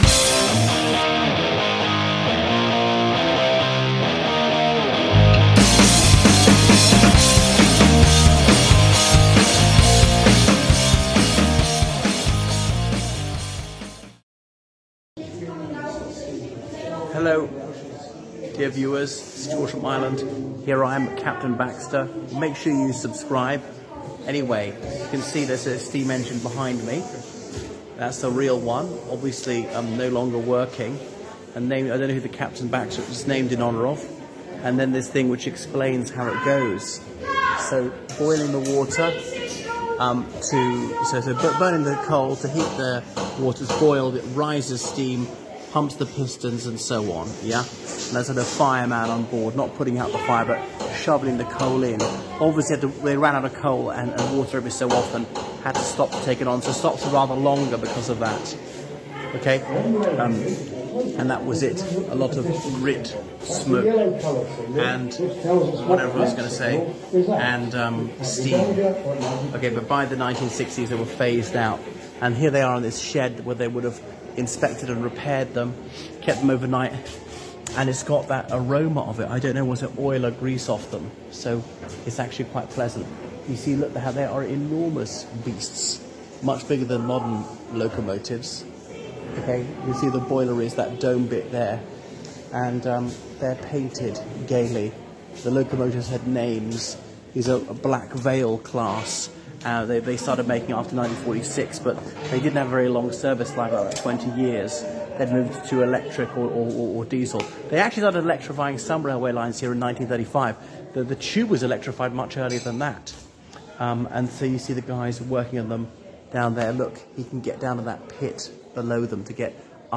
Podcast Episode 05193: Bluebell Railway locomotive shed